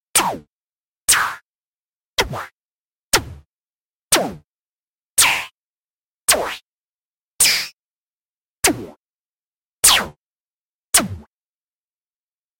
Звуки выстрелов из базуки для игр